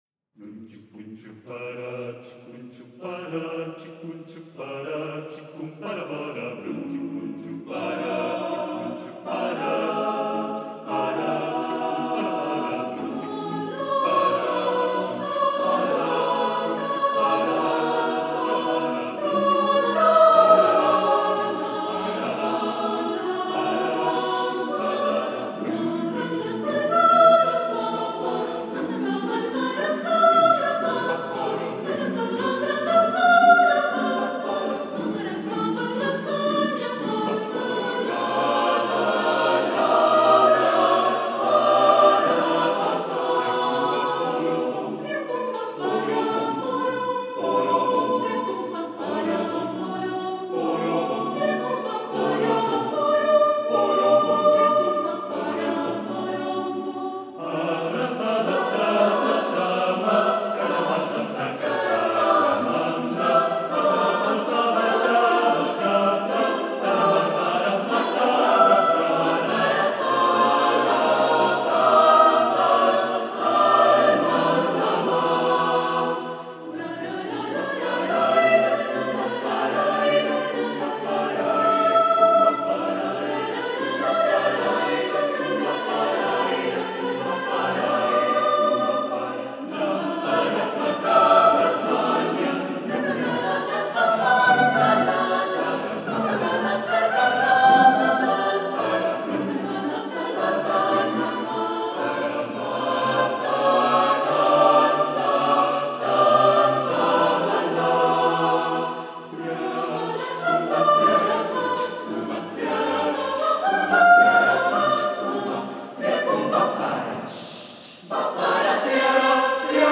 SSAATTBB (8 voix mixtes) ; Partition complète.
Chœur.
Consultable sous : 20ème Profane Acappella